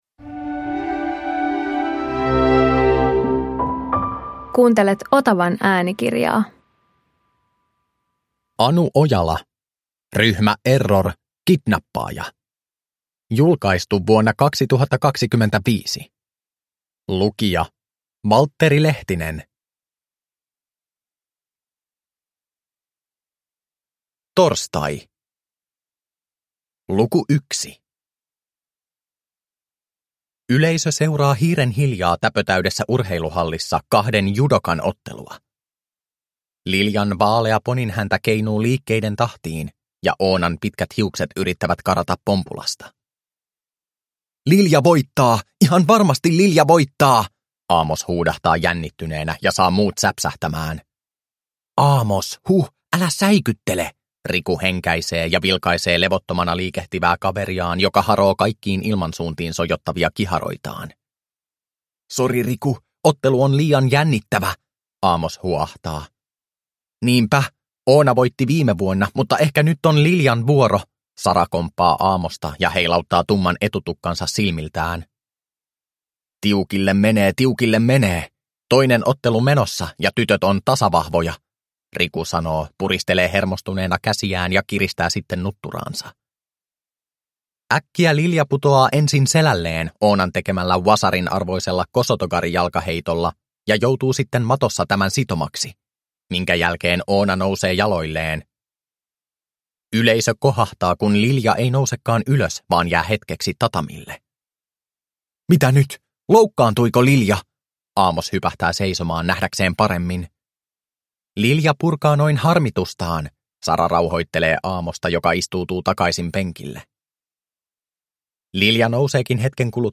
Ryhmä Error - Kidnappaaja – Ljudbok